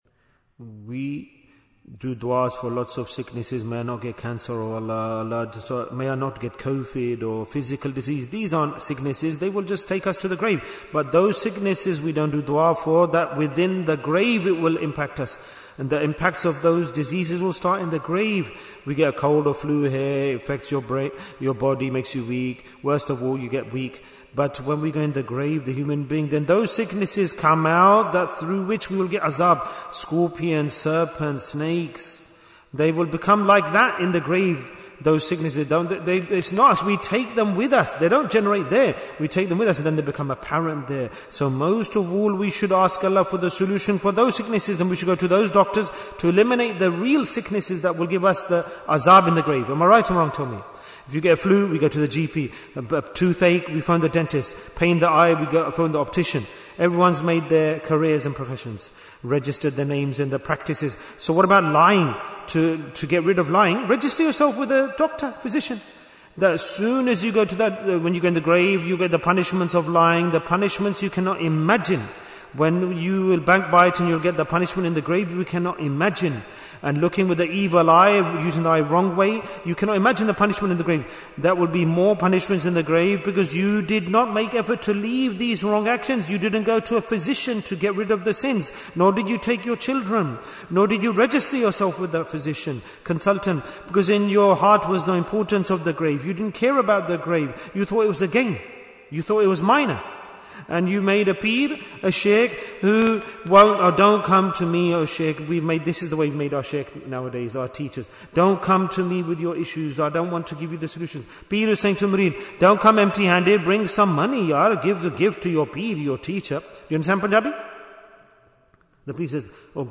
- Part 13 Bayan, 36 minutes 26th January, 2026 Click for Urdu Download Audio Comments Why is Tazkiyyah Important?